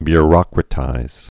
(by-rŏkrə-tīz)